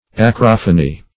Acrophony \A*croph"o*ny\, n. [Gr. 'a`kros extreme + ? sound.]